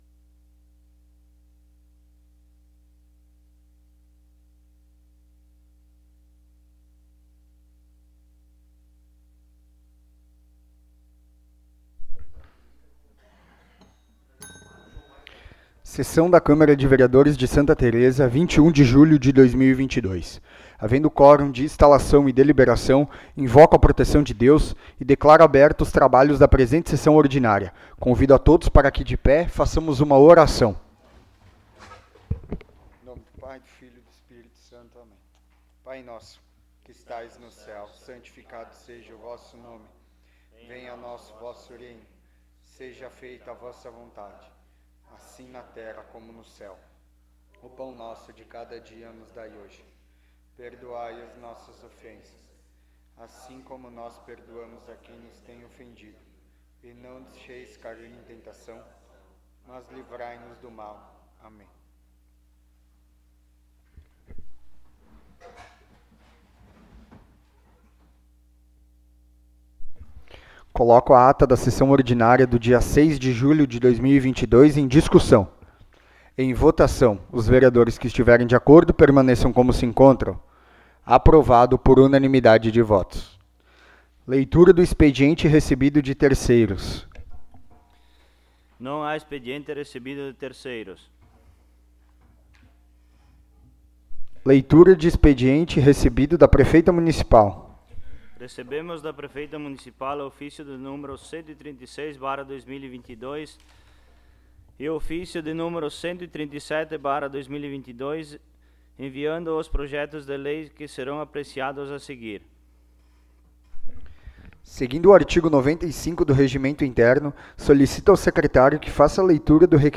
12ª Sessão Ordinária de 2022
Áudio da Sessão
Local: Câmara Municipal de Vereadores de Santa Tereza